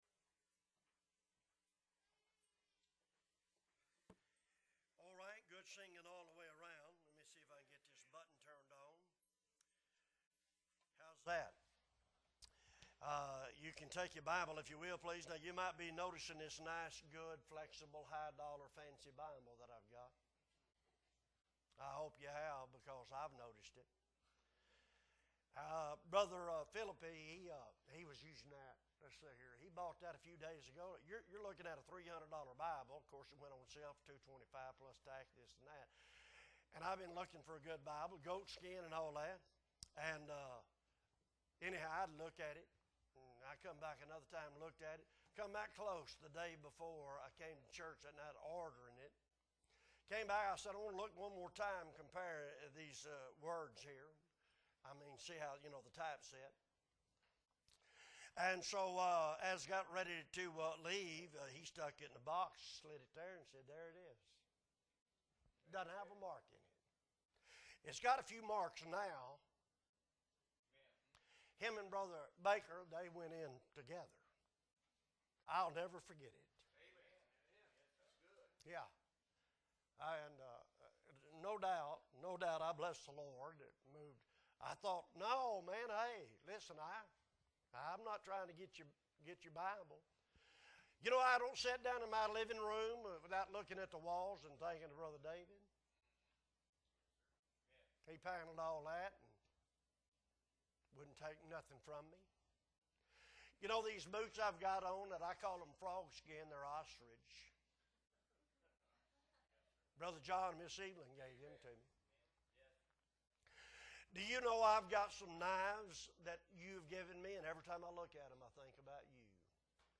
September 18, 2022 Morning Service - Appleby Baptist Church